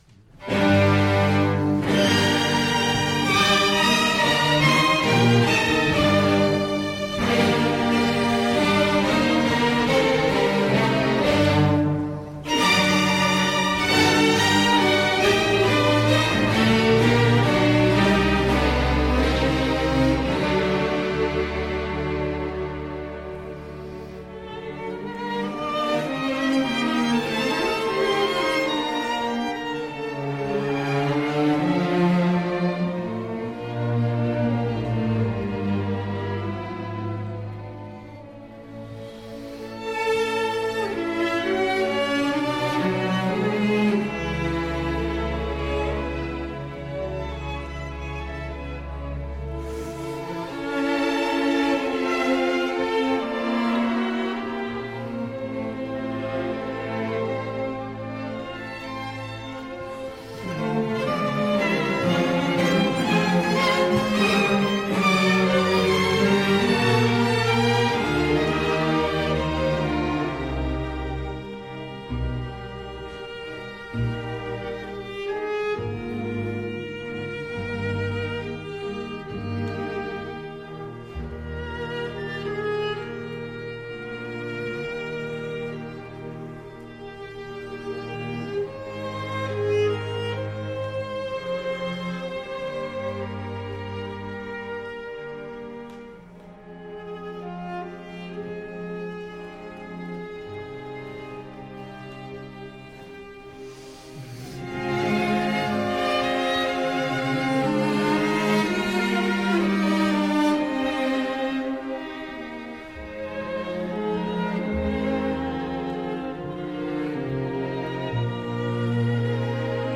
Introduction and Allegro, Op.47 String Ensemble
Style: Classical
Audio: Boston - Isabella Stewart Gardner Museum
Audio: Gardner Chamber Orchestra